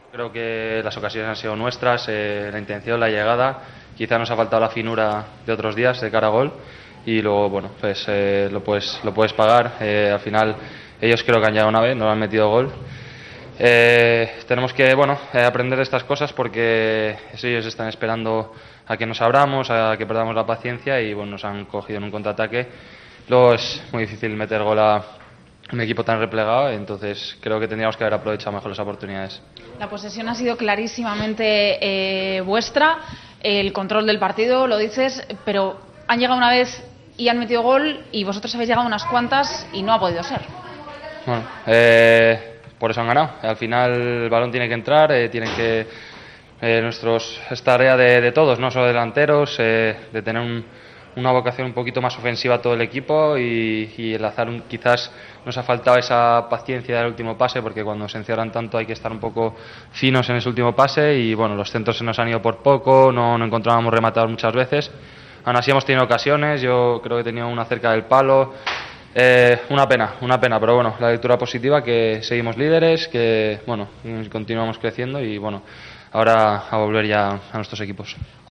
AUDIO: El mediocentro de la selección analiza en TVE la derrota ante Ucrania.